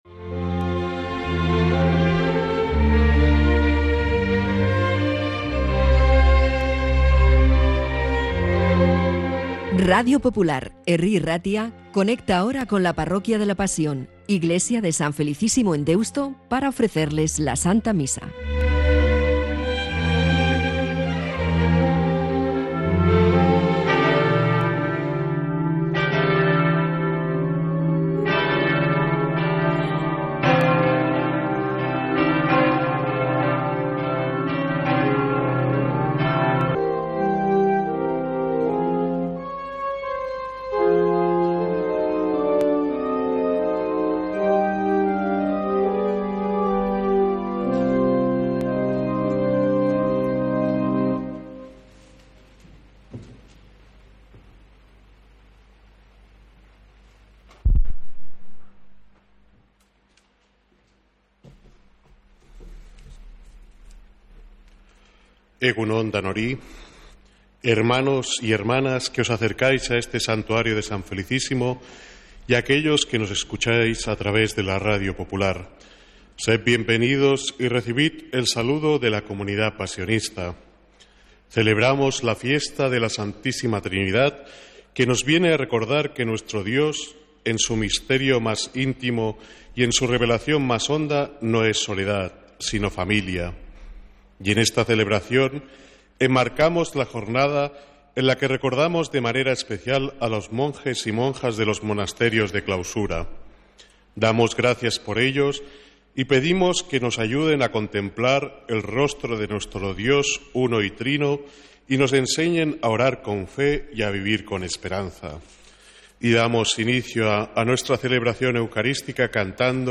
Santa Misa desde San Felicísimo en Deusto, domingo 15 de junio